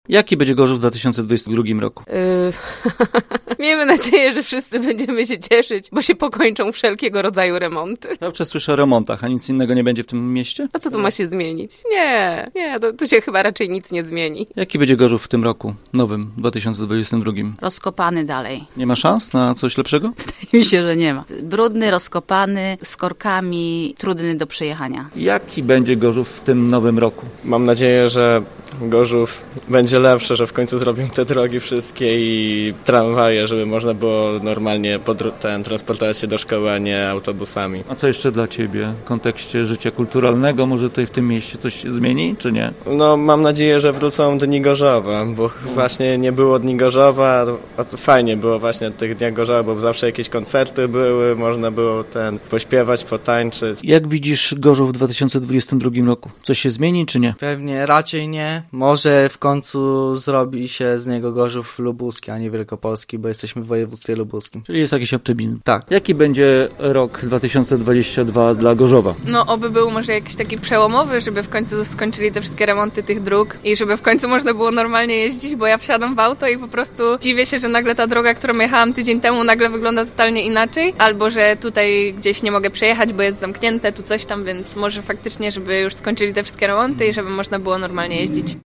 My zapytaliśmy mieszkańców Gorzowa jak widzą nasze miasta z nową cyfrą w kalendarzu?